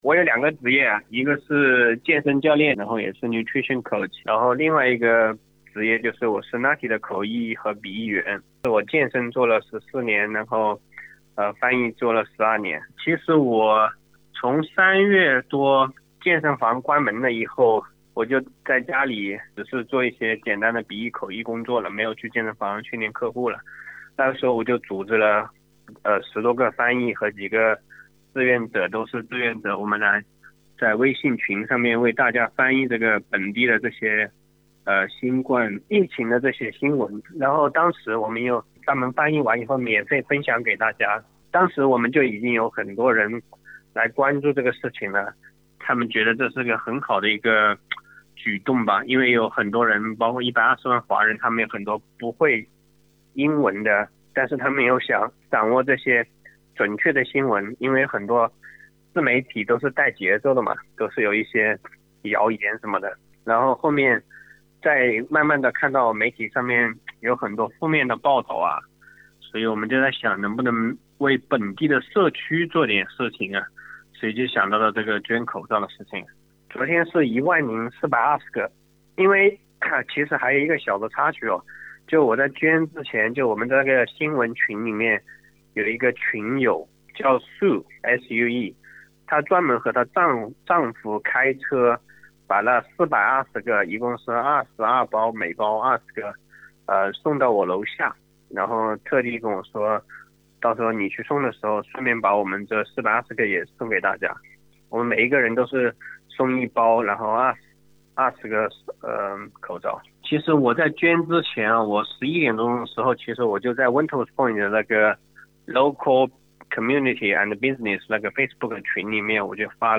READ MORE 十天之内这群华人为悉尼医院送去1000个口罩 点击本文最上方图片可以收听完整采访。